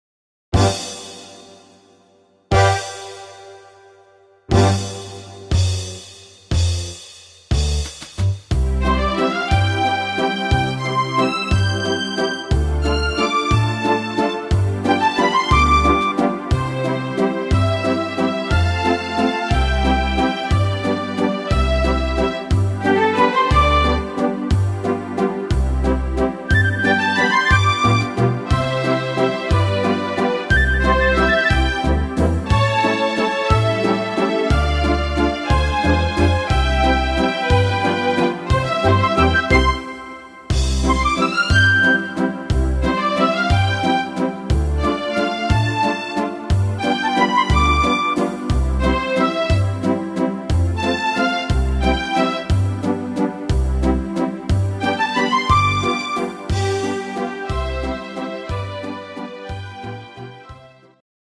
instr. Strings